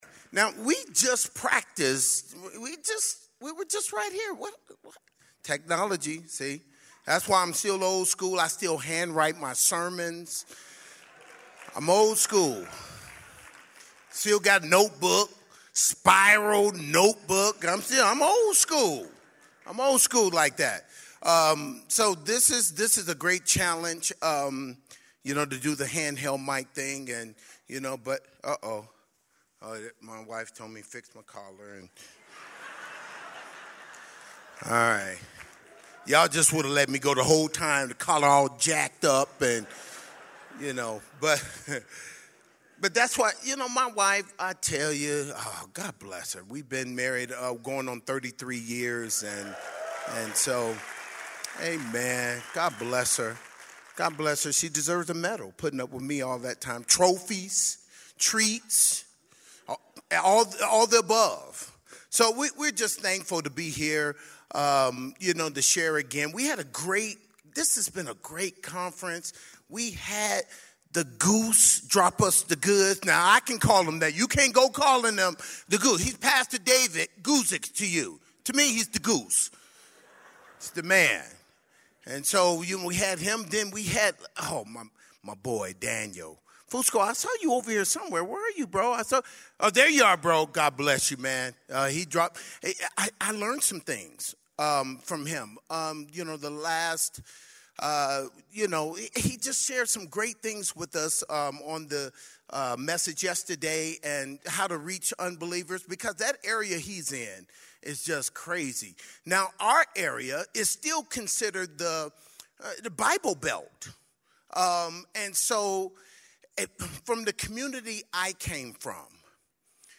2018 Southwest Pastors and Leaders Conference